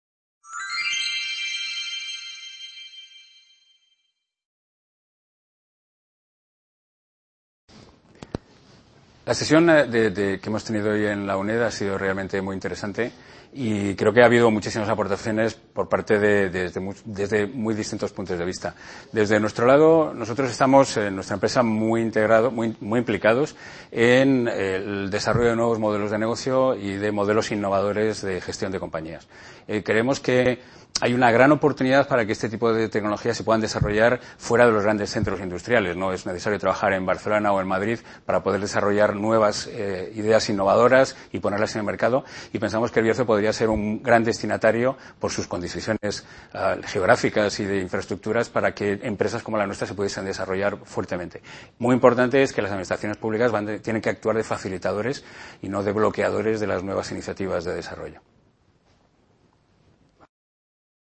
VI Edición del Festival Villar de los Mundos - El PASADO de LOS BARRIOS y el FUTURO de EL BIERZO
Video Clase